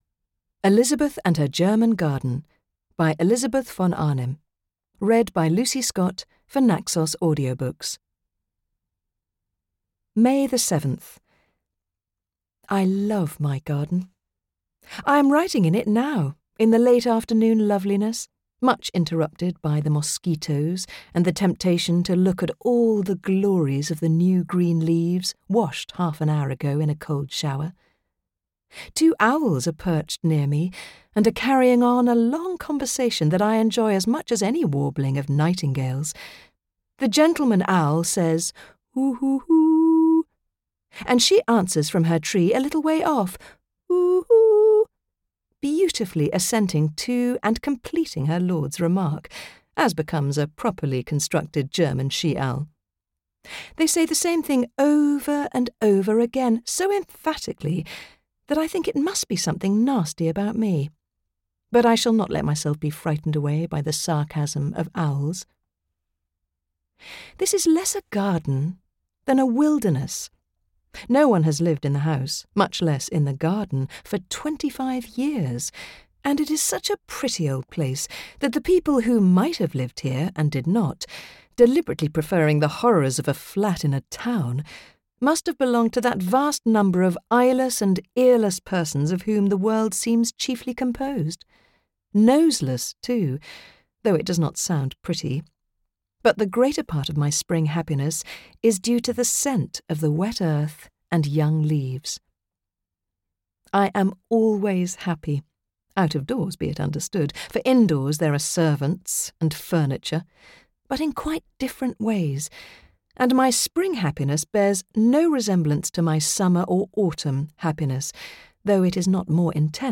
Elizabeth and Her German Garden audiokniha
Ukázka z knihy